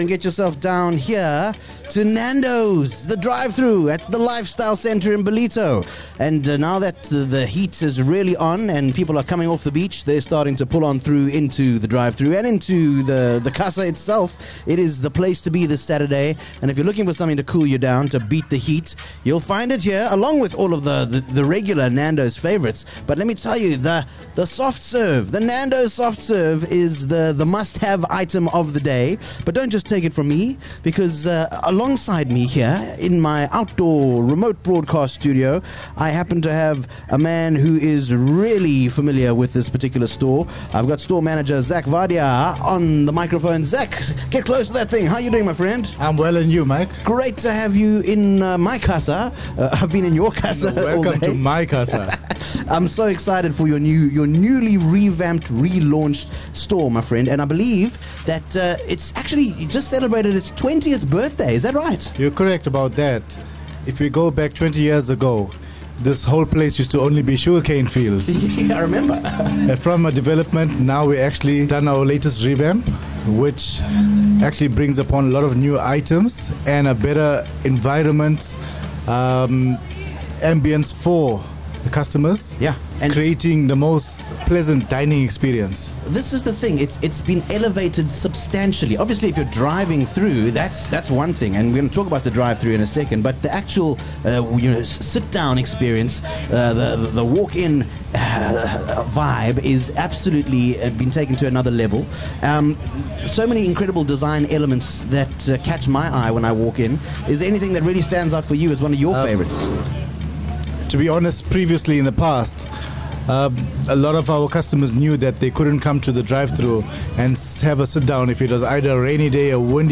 7 Oct Live from the Casa